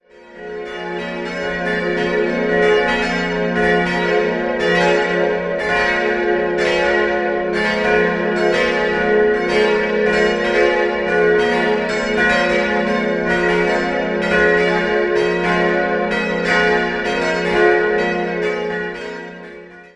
Die heutige katholische Pfarrkirche St. Peter und Paul stammt aus dem Jahr 1925, wobei die Innenausstattung aus dem Vorgängerbau übernommen wurde. 4-stimmiges ausgefülltes F-Moll-Geläute: f'-as'-b'-c'' Alle Glocken wurden von Friedrich Wilhelm Schilling gegossen.